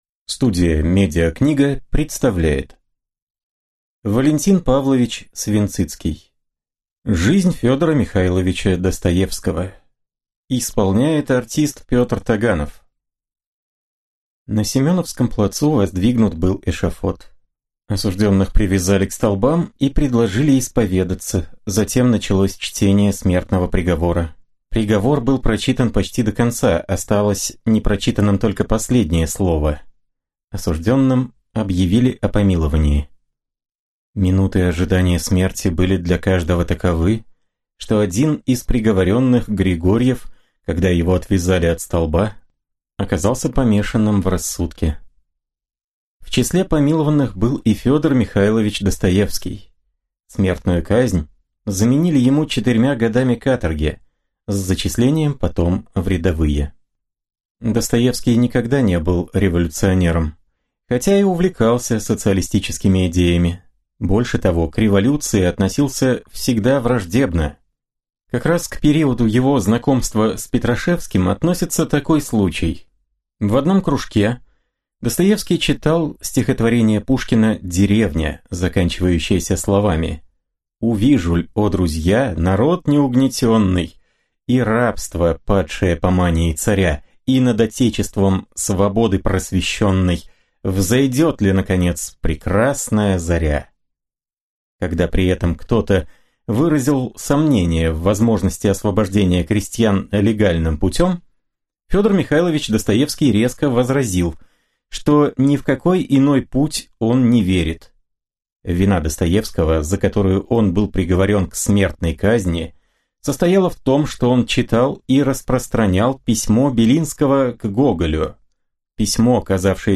Аудиокнига Жизнь Ф. М. Достоевского | Библиотека аудиокниг